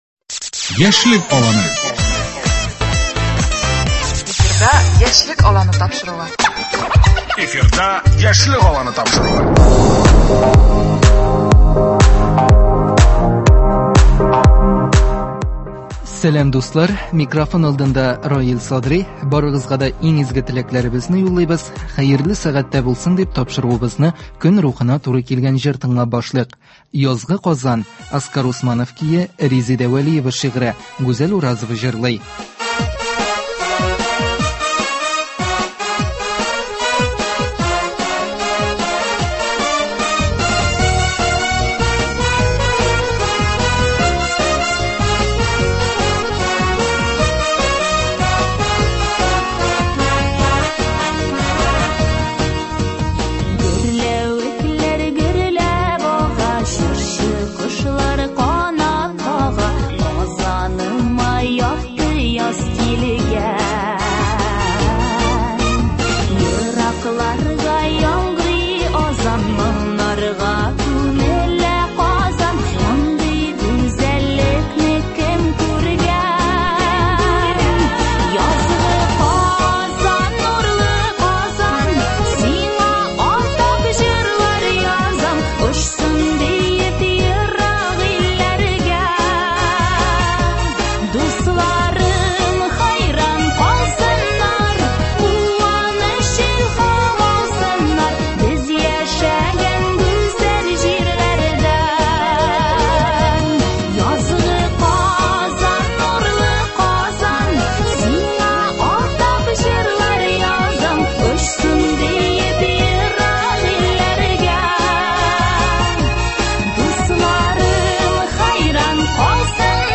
Күңелнең нәкъ менә шигырьгә, назлы сүзгә сусаган мәле. Нәкъ шуны истә тотып бүген без студиябезгә кунакка яшь иҗатчы